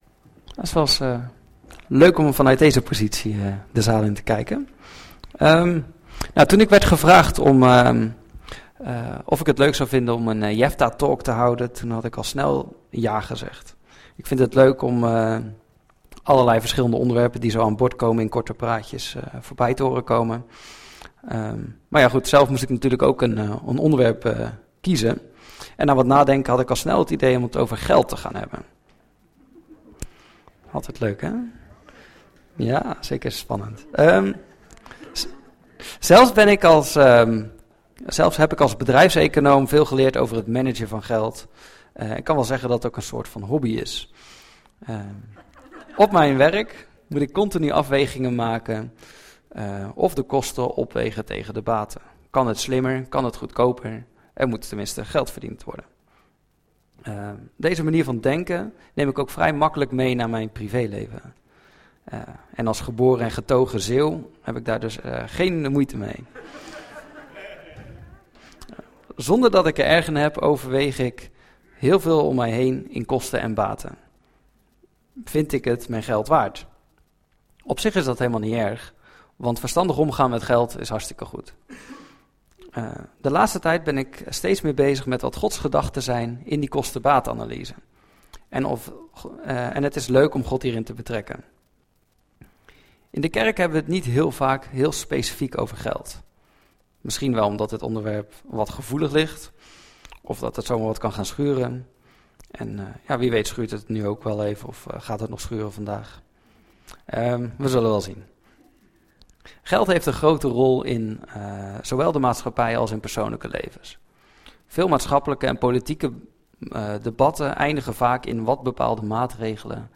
Preken – Evangelische Kerk Jefta